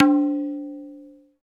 PRC TABLA 04.wav